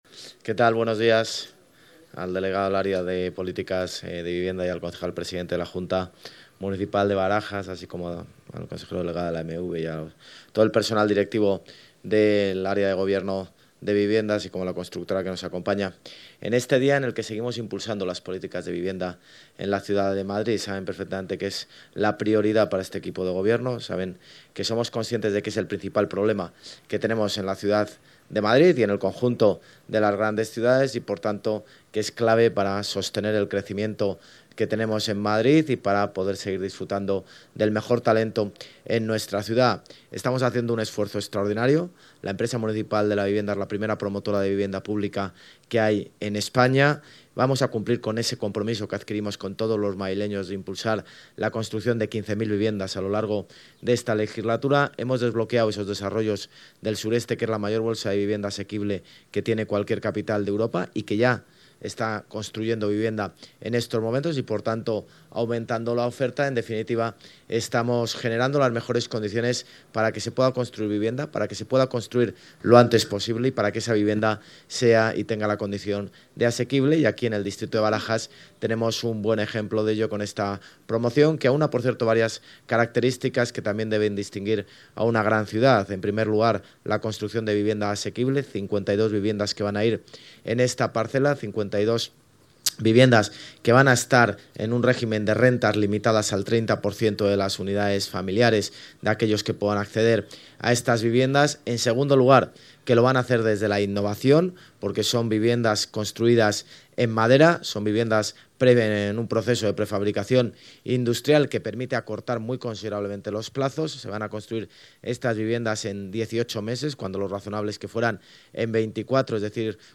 Nueva ventana:Intervención del alcalde de Madrid, José Luis Martínez-Almeida, en la colocación de la primera piedra de la promoción Iberia Loreto 1 de EMVS Madrid
(AUDIO) INTERVENCIÓN ALCALDE PRIMER EDIFICIO DE VP DE MADERA EN MADRID.mp3